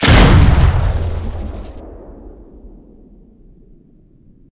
pistol2.ogg